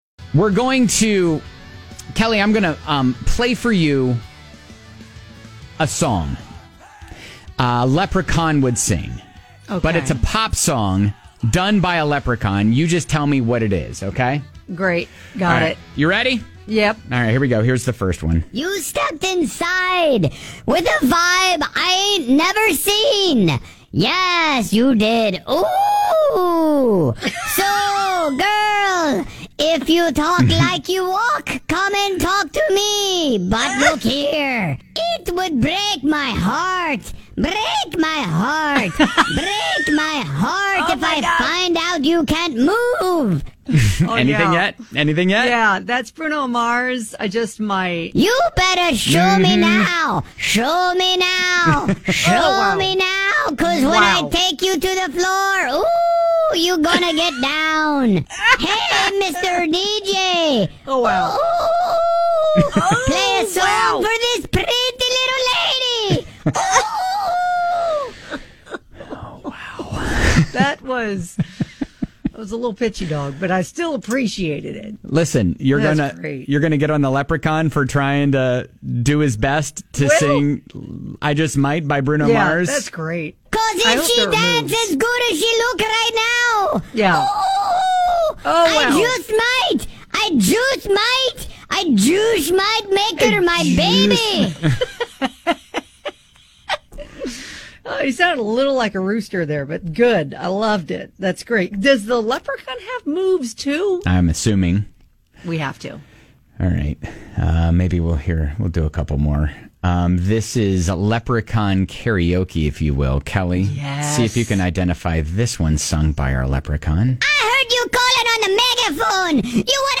The bar for comedy on our show is pretty low. Can you guess the song our Leprechaun is singing?